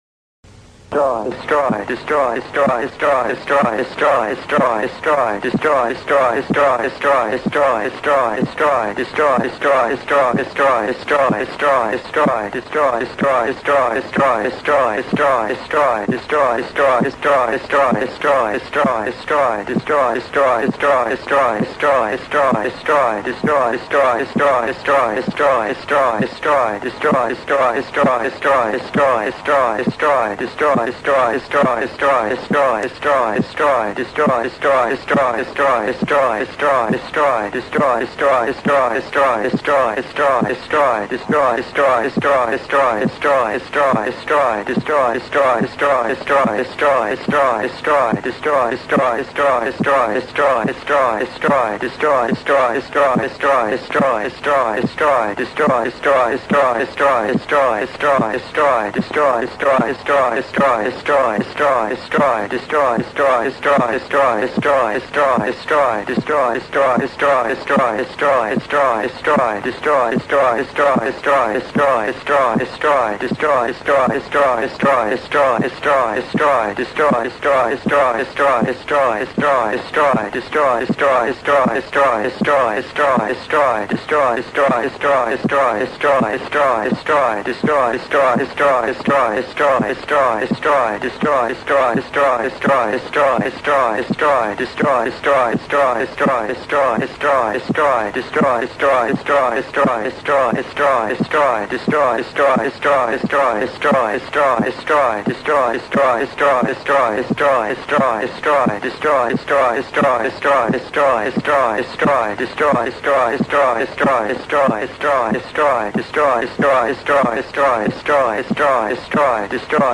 "Suddering Words" is a monthly improvised mashup of various radio show formats from rock jock to radio drama to NPR to old time mysteries and everything in between. It is unattainable gibberish in its perfect form. There will be songs, news, stories, manipulated cassettes, and some in-studio visits by demons AND angels, and it all takes place in a village of airwaves dominated by slittering sounds and suddering words.